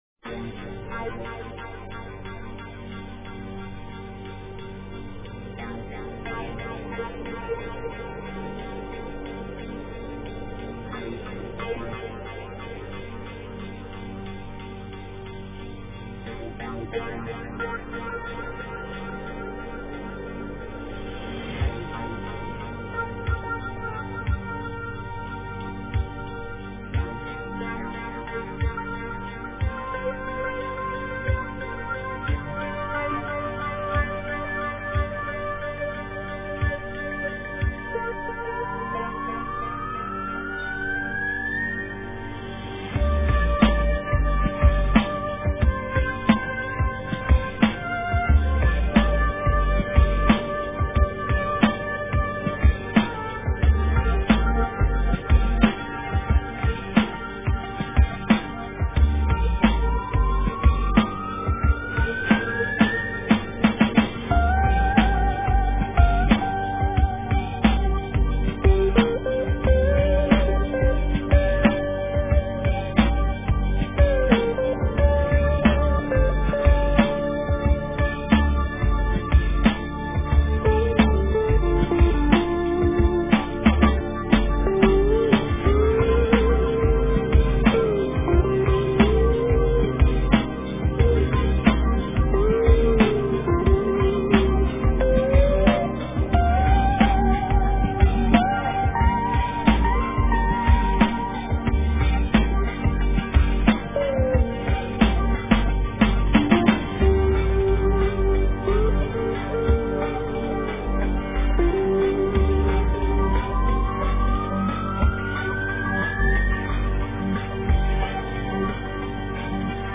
Назад в Drum&Bass
DNB
Советую, очень приятный микс